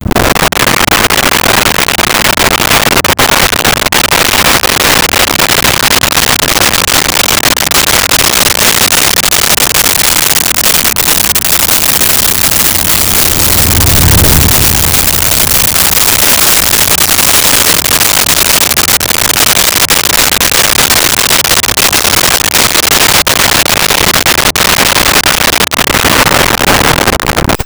Muscle Car Slow By 10MPH
Muscle Car Slow By 10MPH.wav